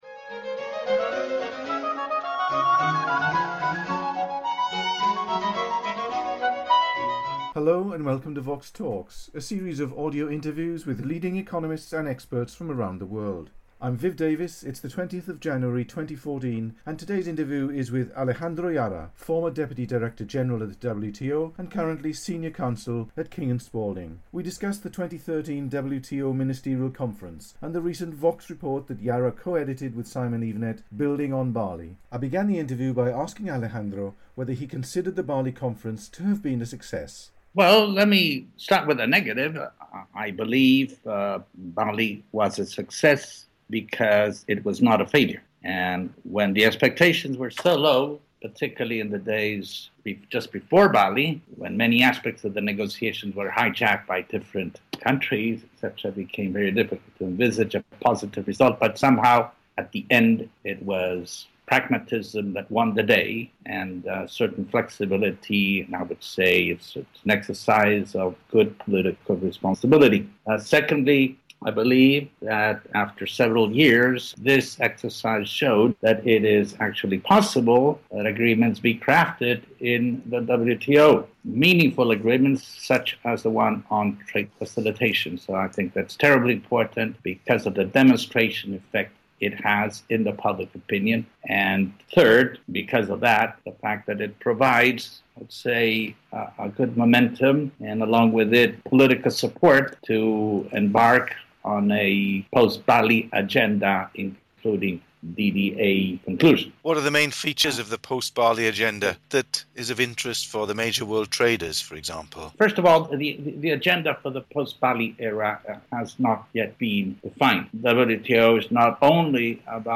The interview was recorded in January 2014.